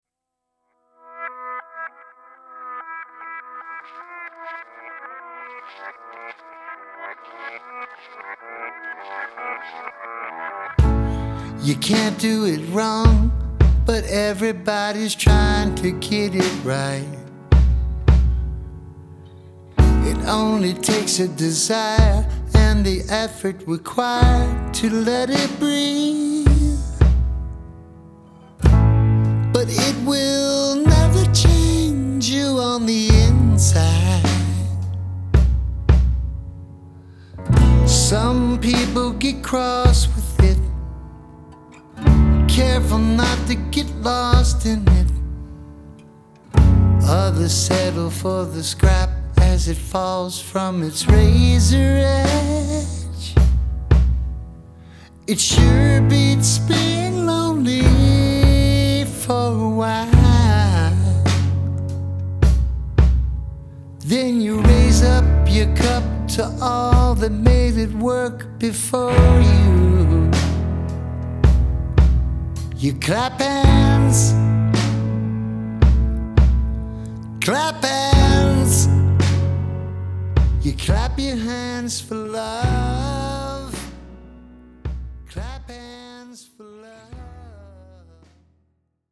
guitar, drums, vocals
bass
piano, organ